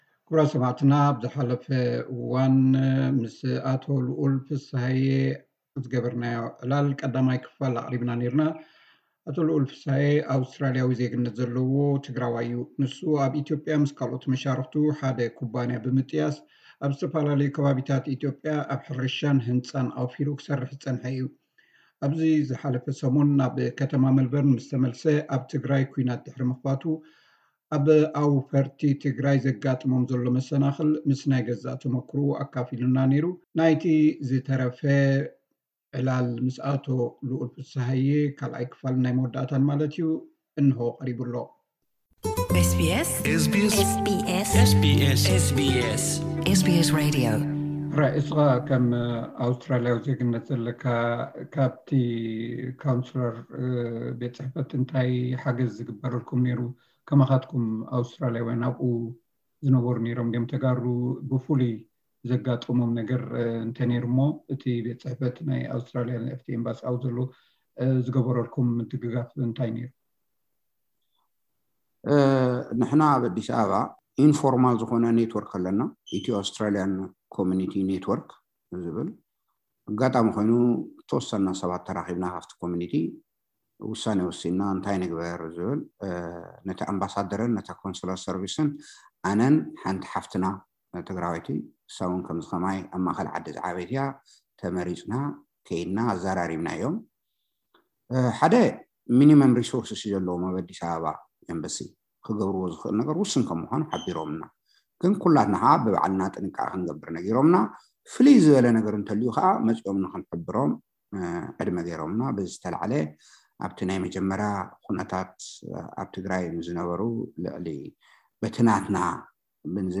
ዝገበርናዮ ቃለ መሕትት እዩ።